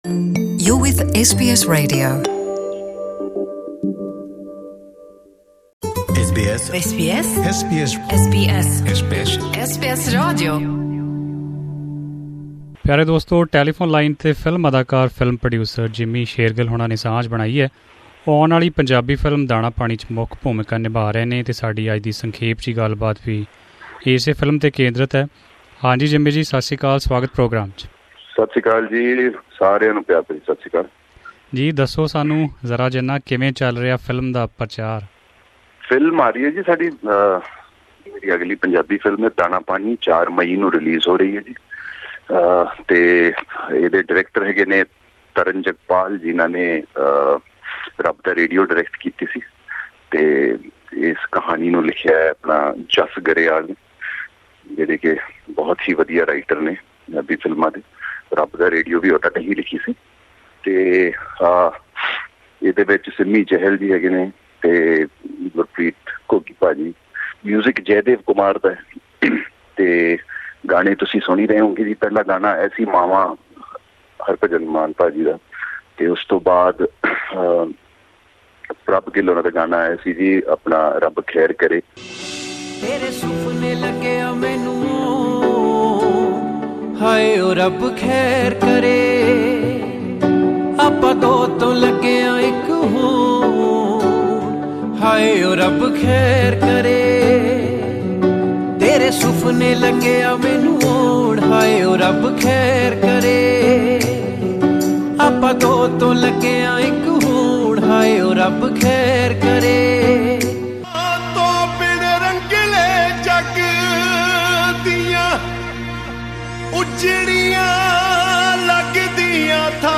In an interview with SBS Punjabi, Jimmy Shergill said that the movie will prove a bundle of entertainment for all age groups.